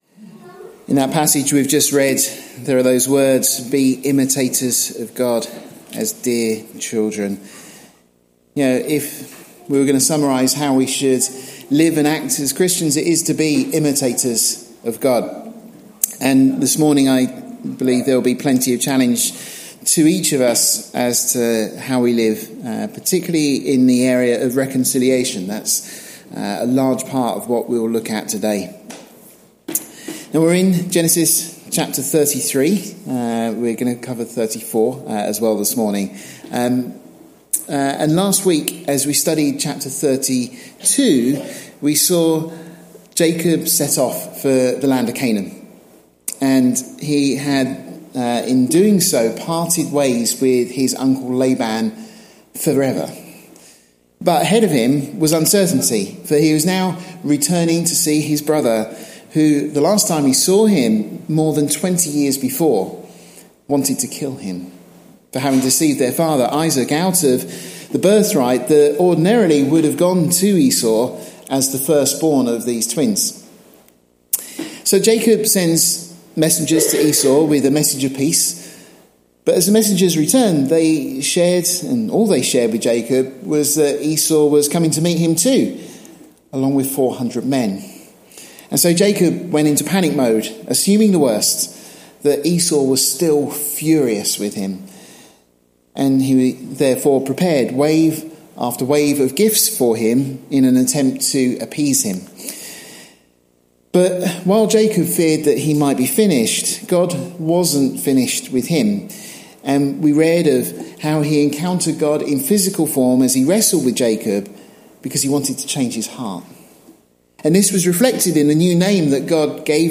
This study is part of our series of verse by verse studies of Genesis, the 1st book in the Bible.